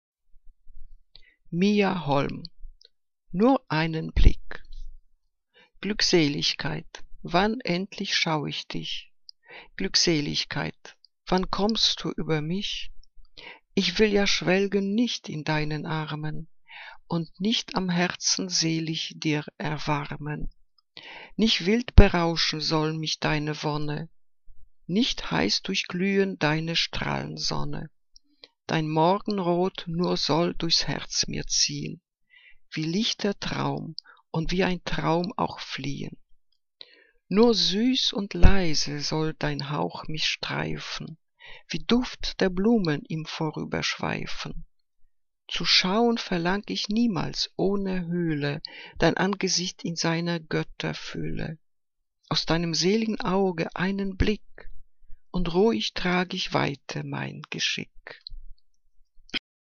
Ausgewählte Liebesgedichte